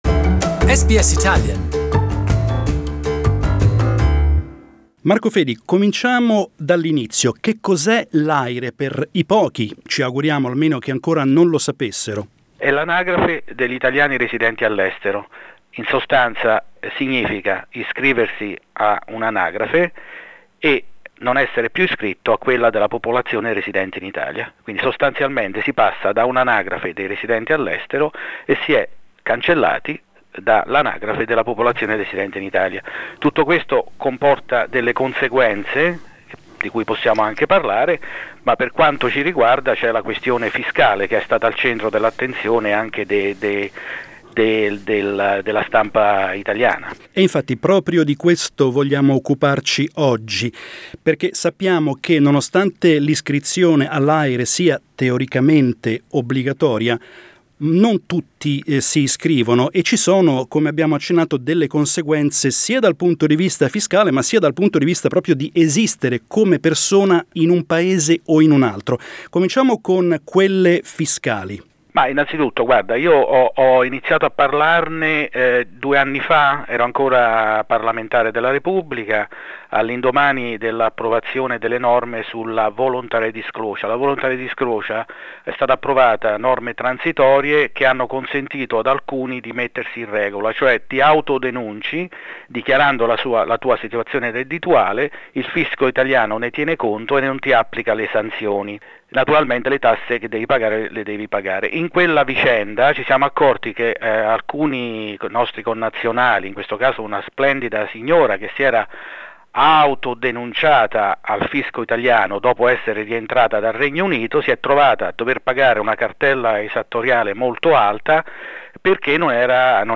Allo scopo di evitare loro di trovarsi brutte sorprese nella casella della posta, abbiamo raggiunto telefonicamente Marco Fedi, ex deputato alla Camera eletto in Australia e ora impegnato in un altro ruolo nell'ambito della comunità italiana, per fare chiarezza sulla vicenda, chiedendogli innanzitutto di spiegare, per chi ancora non lo sapesse, che cos'è l'AIRE.